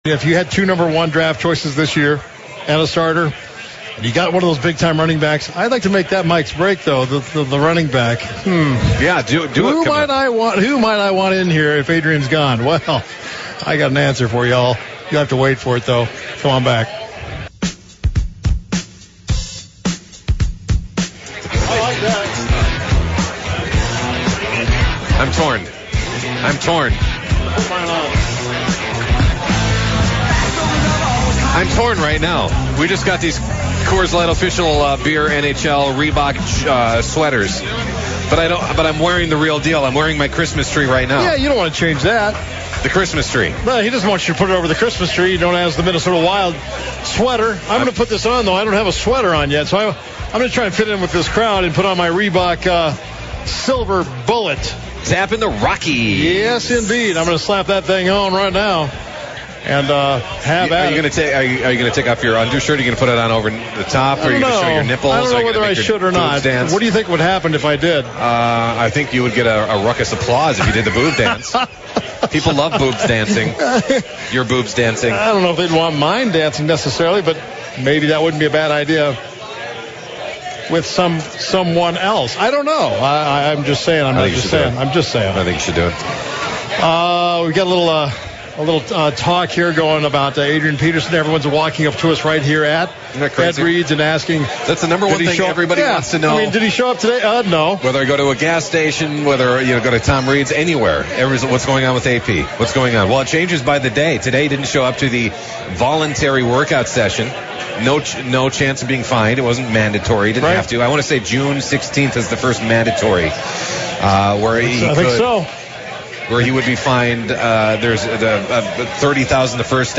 live from Tom Reid's Hockey City Pub